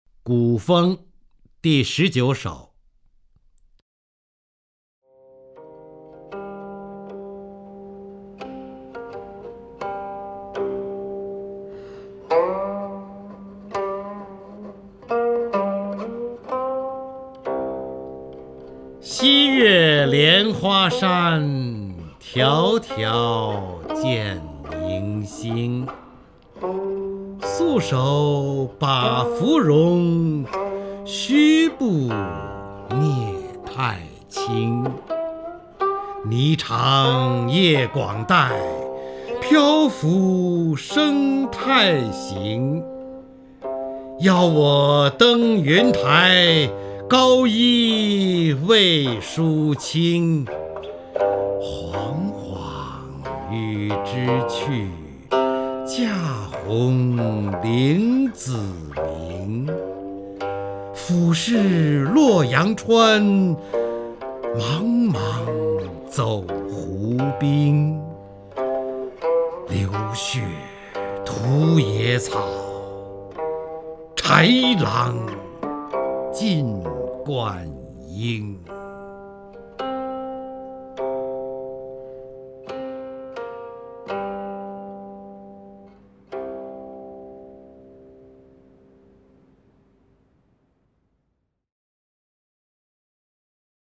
方明朗诵：《古风（第十九首）》(（唐）李白)
名家朗诵欣赏 方明 目录